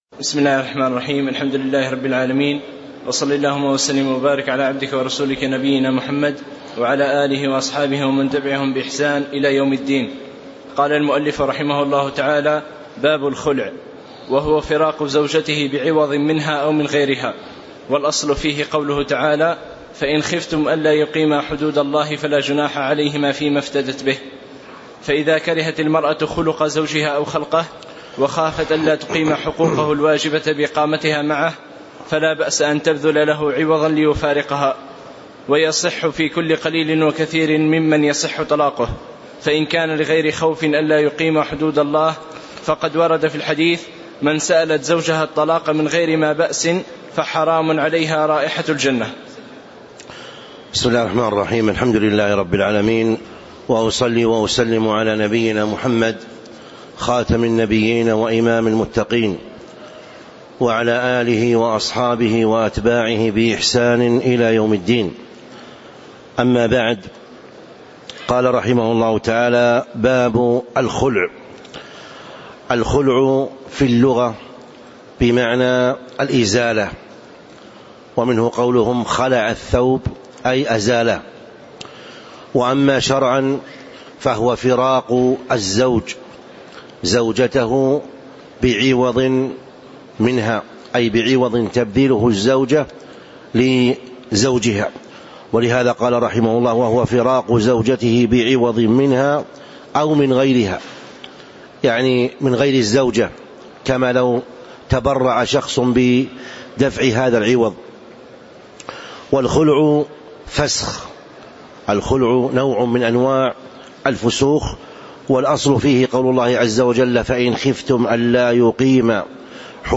تاريخ النشر ٢٥ جمادى الآخرة ١٤٤٦ هـ المكان: المسجد النبوي الشيخ